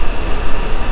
generator02.wav